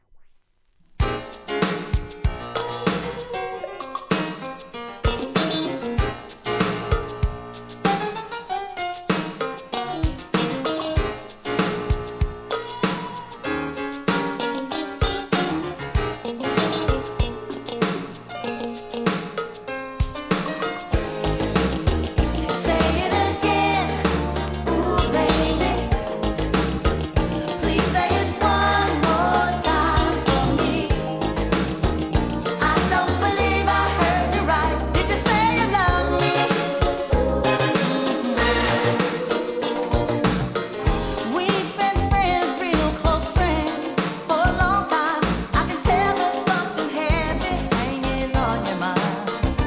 ¥ 660 税込 関連カテゴリ SOUL/FUNK/etc...
程よく跳ねる気持ちが良いメロディアス・ダンス・ナンバー♪